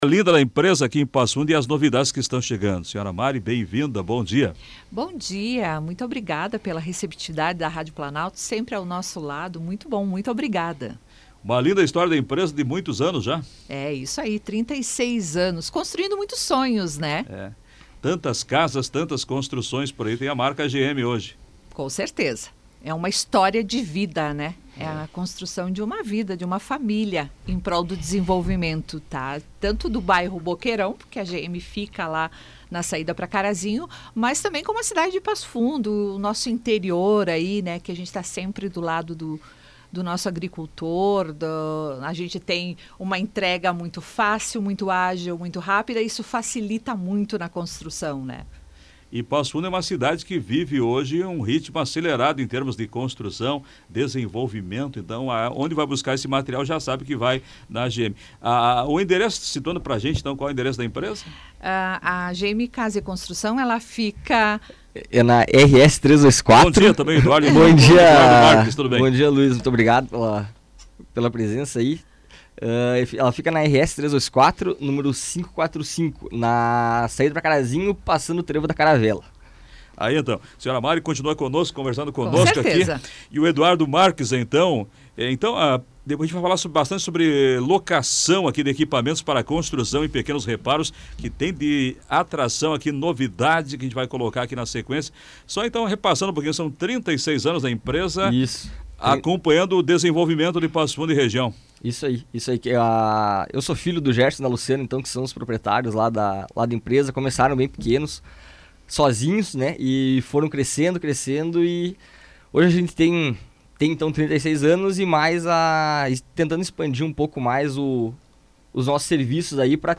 participaram do programa Comando Popular, da Rádio Planalto News (92.1), e apresentaram as vantagens e produtos disponibilizados para os clientes de toda a região. É possível locar desde a betoneira até o contêiner, garantindo a agilidade do início ao fim da obra.